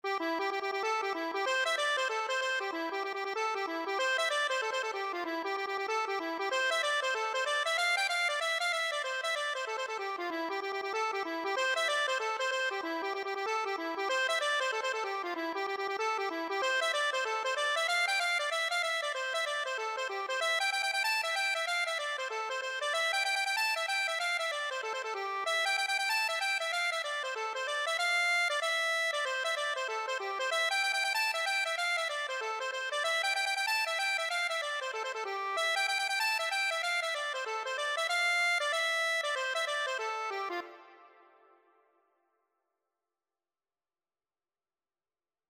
C major (Sounding Pitch) (View more C major Music for Accordion )
4/4 (View more 4/4 Music)
Accordion  (View more Intermediate Accordion Music)
Traditional (View more Traditional Accordion Music)
Irish
silver_spear_ACC.mp3